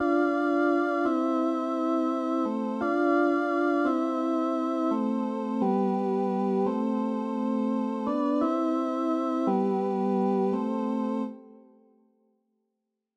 Ist übrigens das Preset "Vox Continental" aus dem Air Xpand 2. your_browser_is_not_able_to_play_this_audio